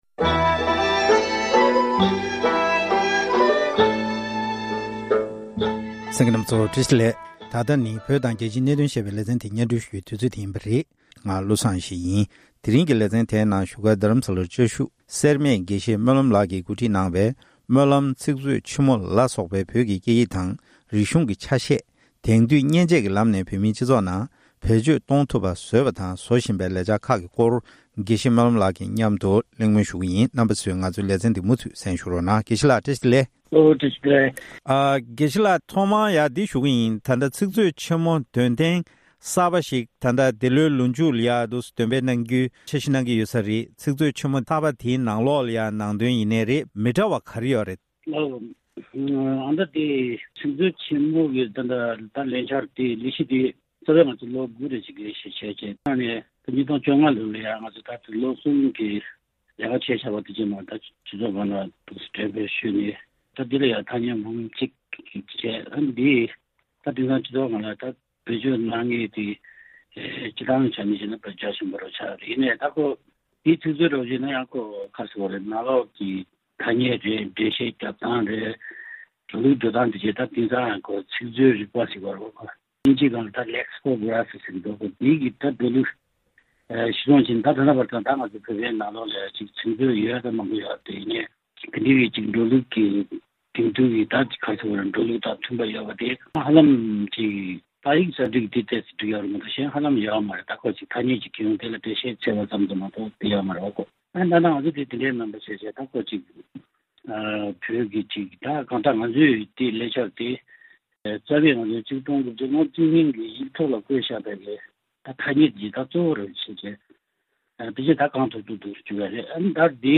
གླེང་མོལ་ཞུས་པར་གསན་རོགས༎